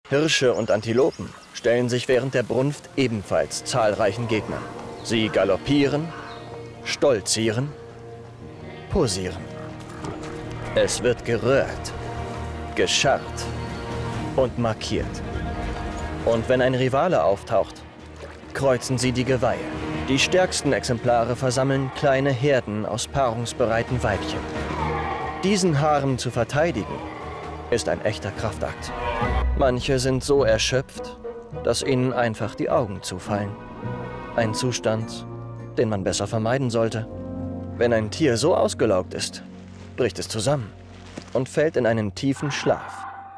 hell, fein, zart, markant, sehr variabel
Jung (18-30)
Audio Drama (Hörspiel)